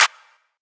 Clap (8) Pluggz-24b.wav